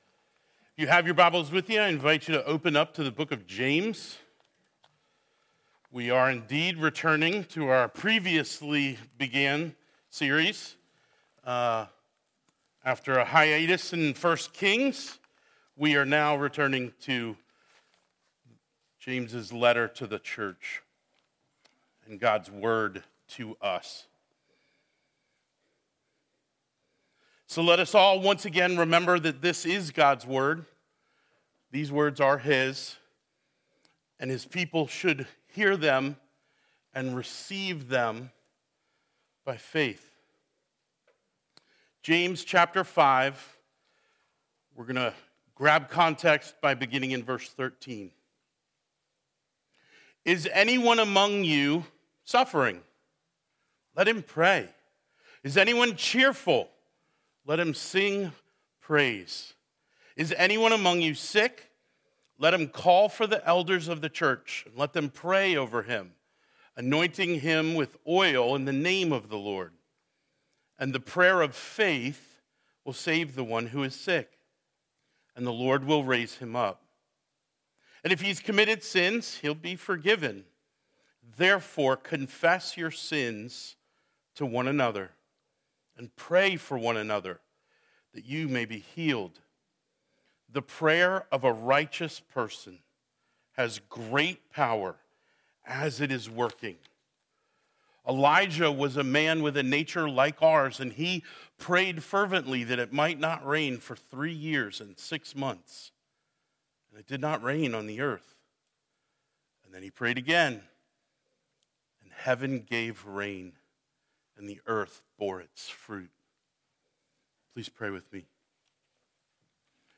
Passage: James 5:13-18 Service Type: Sunday Morning